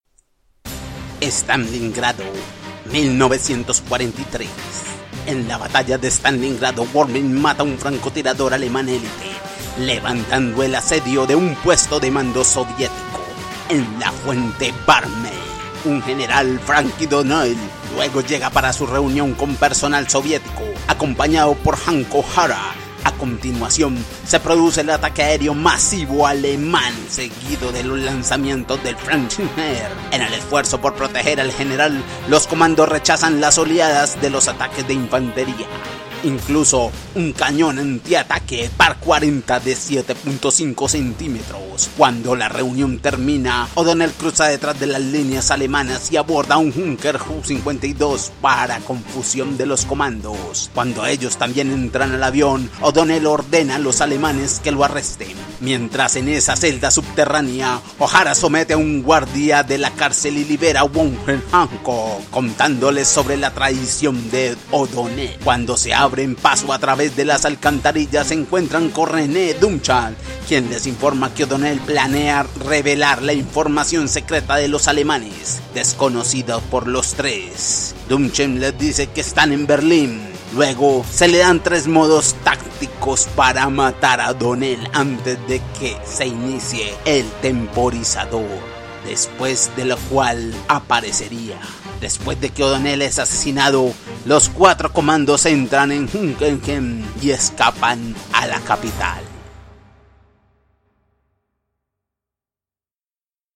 spanisch Südamerika
kolumbianisch
Sprechprobe: Sonstiges (Muttersprache):